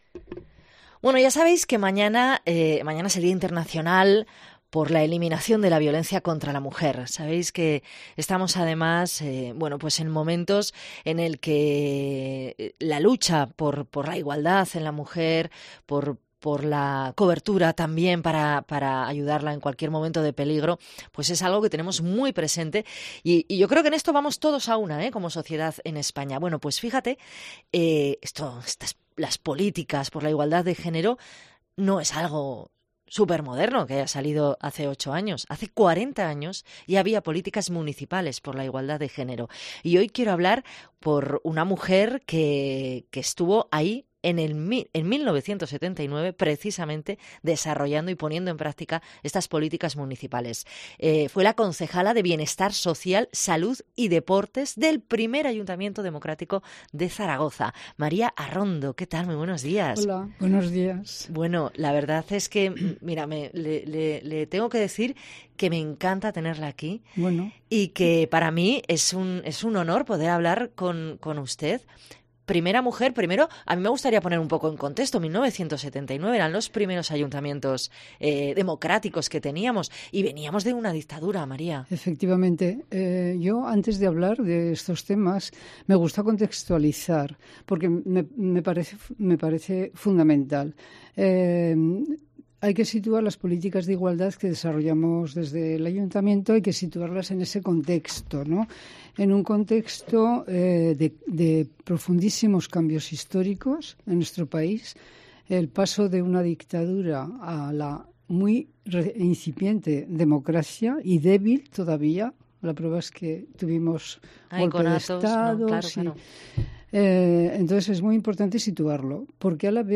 Entrevista a María Arrondo, primera concejala de Igualdad del Ayuntamiento de Zaragoza.